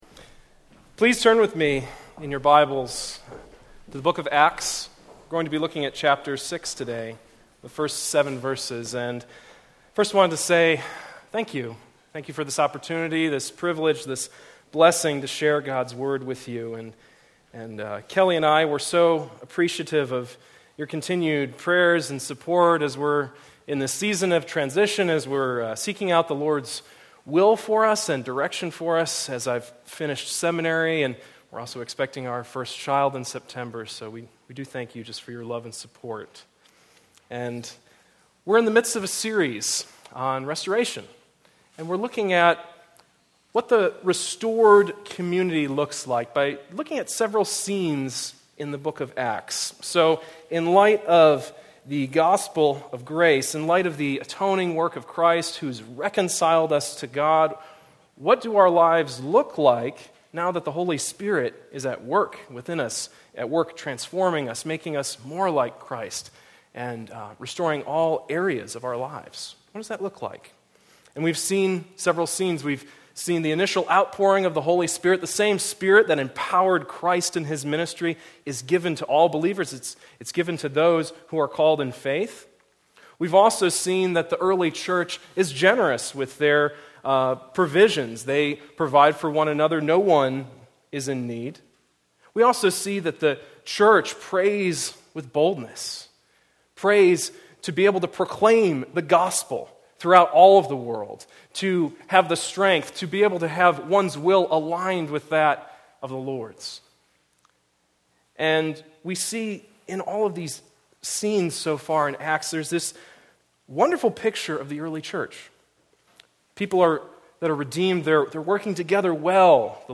Passage: Acts 6:1-7 Service Type: Weekly Sunday